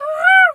pgs/Assets/Audio/Animal_Impersonations/crow_raven_call_01.wav at master
crow_raven_call_01.wav